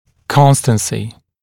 [‘kɔnstənsɪ][‘констэнси]постоянство, неизменность, константность